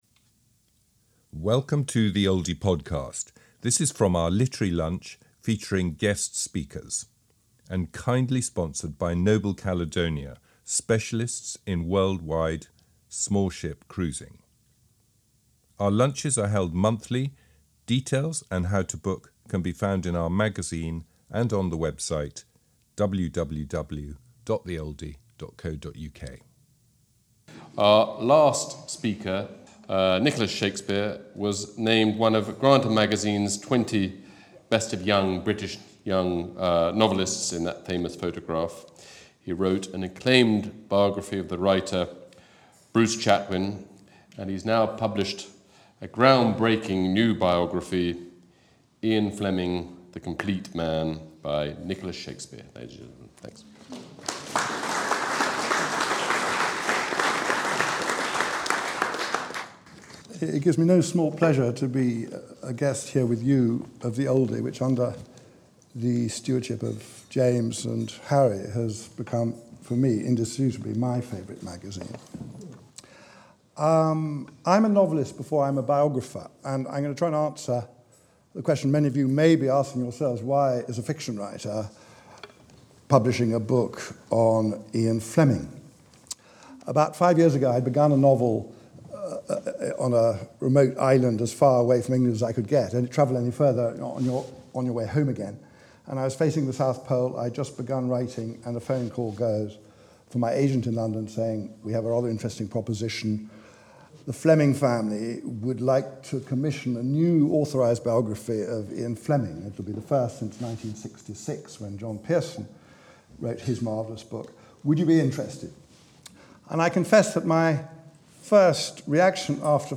Oldie Literary Lunch Recording - Nicholas Shakespeare's favourite magazine?